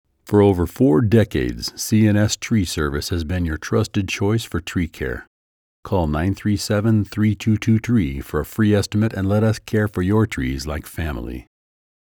Commercial samples.
Local Tree Service 15-second spot (12-seconds for edits & bumper music):